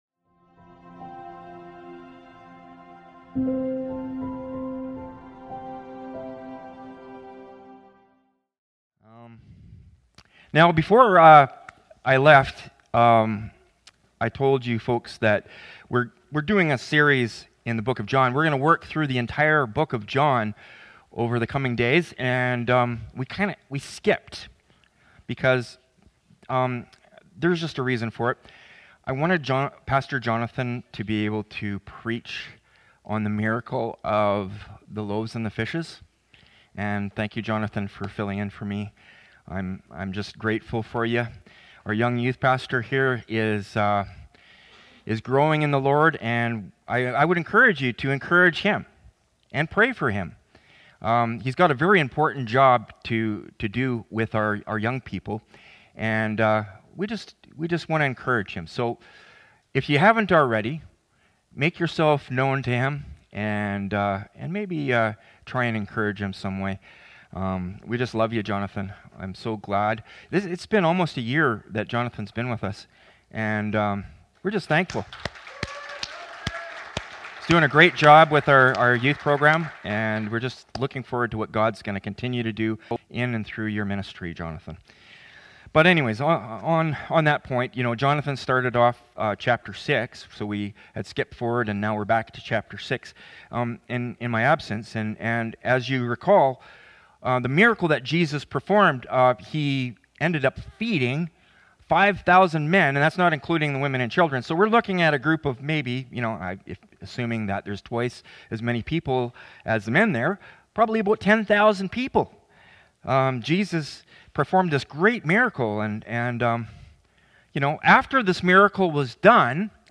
Sermons | Hillside Community Church